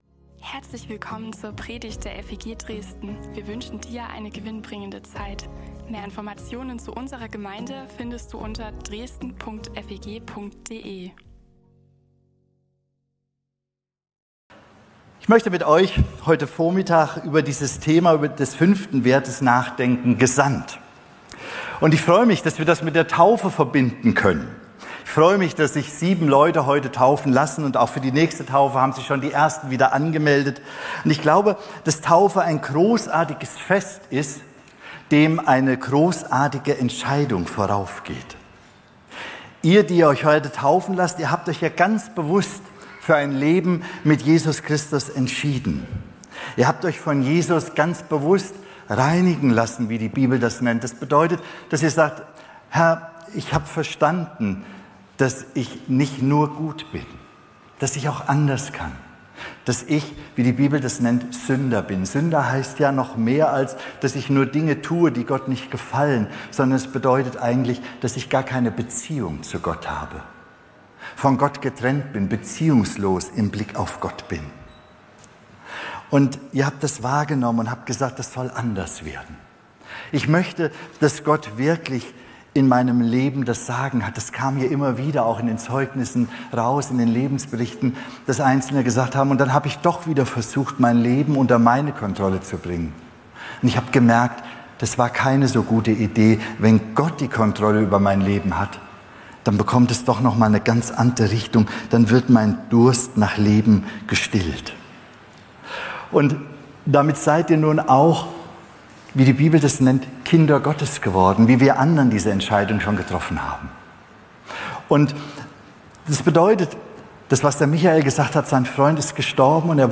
Predigten und mehr